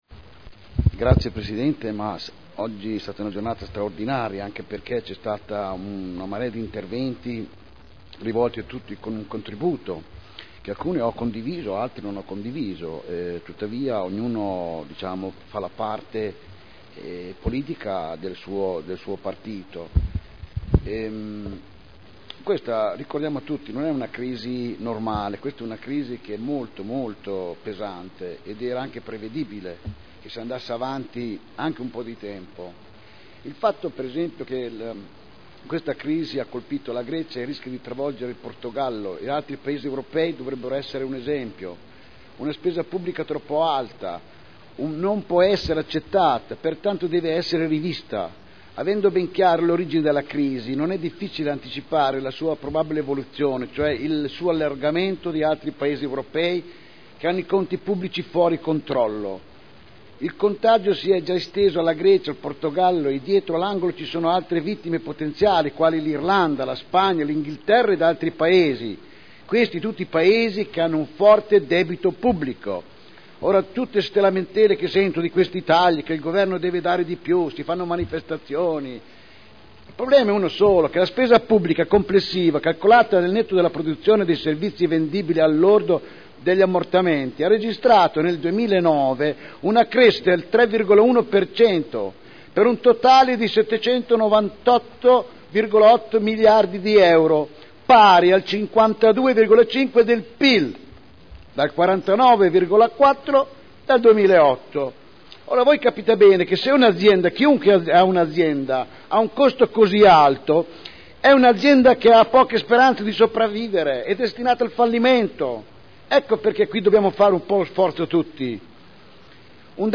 Mauro Manfredini — Sito Audio Consiglio Comunale